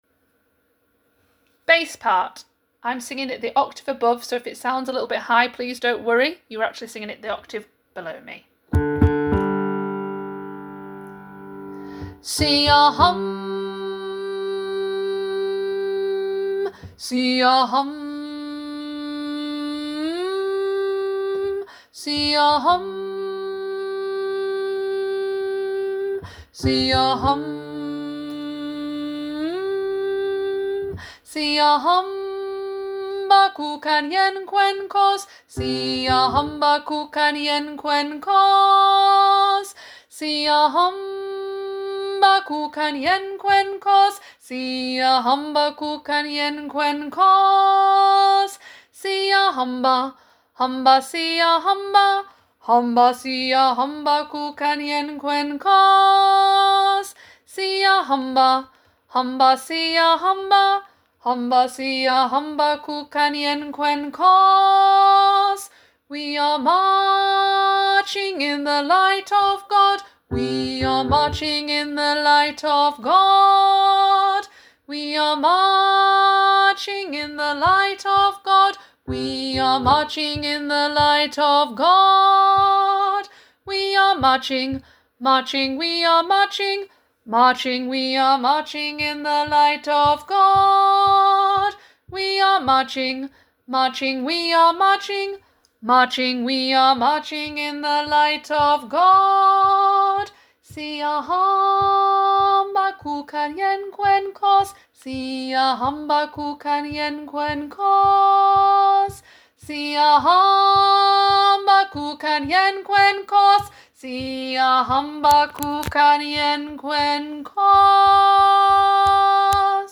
Siyahamba- Bass